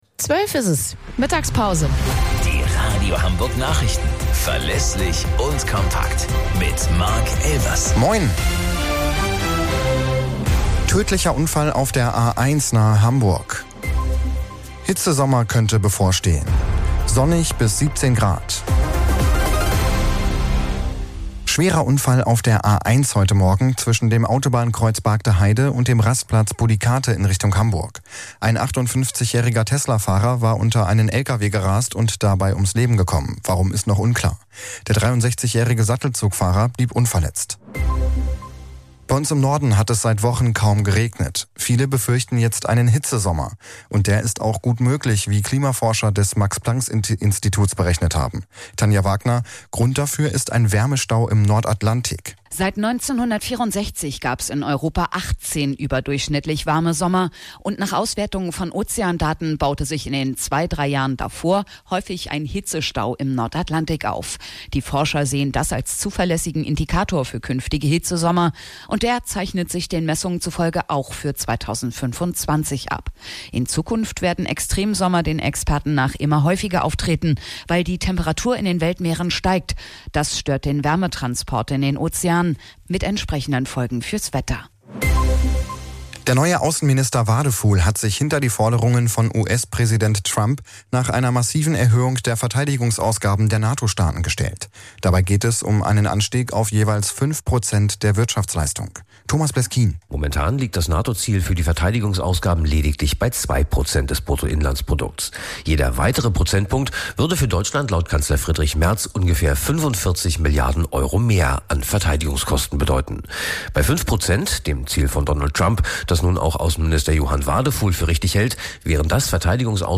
Radio Hamburg Nachrichten vom 15.05.2025 um 19 Uhr - 15.05.2025